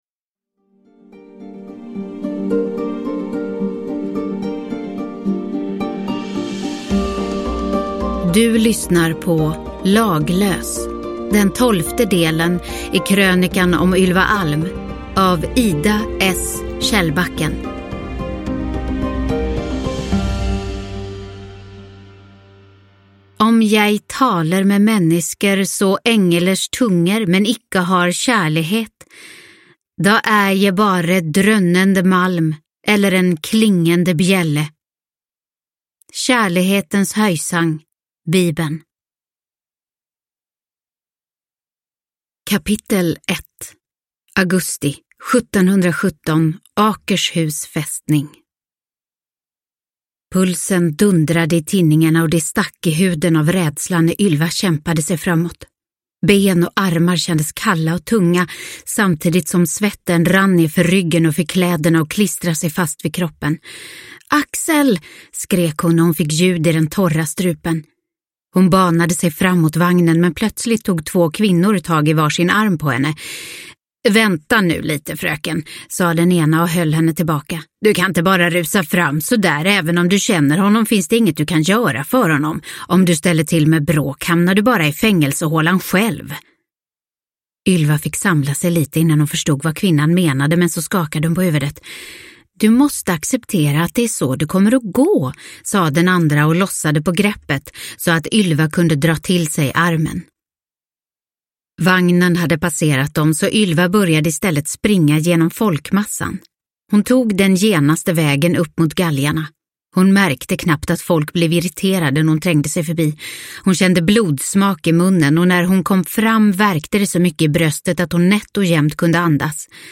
Laglös – Ljudbok – Laddas ner